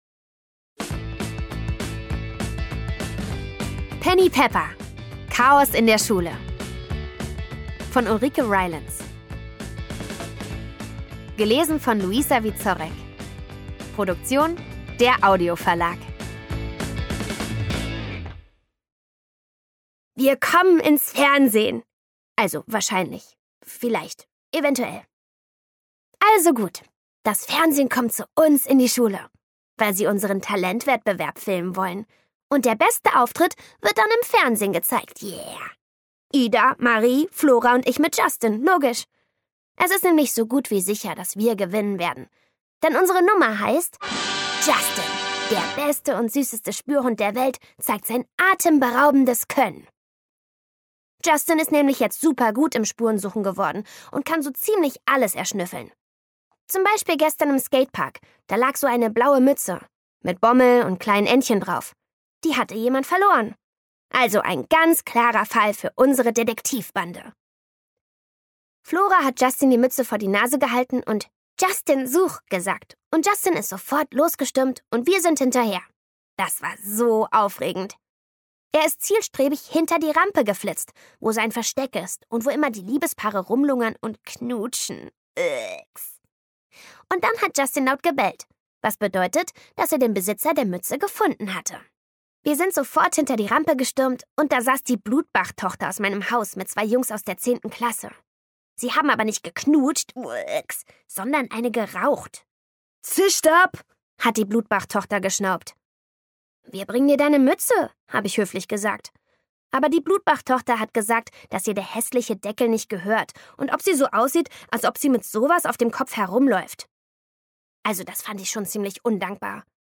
Penny Pepper – Teil 3: Chaos in der Schule Szenische Lesung mit Musik